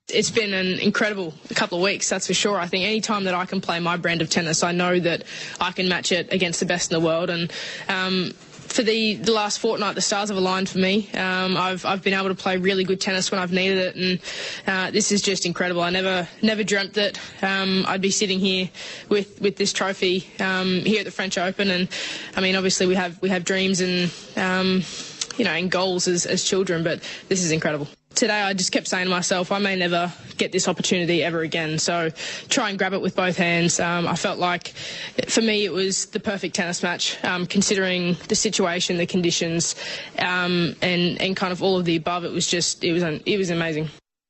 Ash Barty interview
82742-ash-barty-interview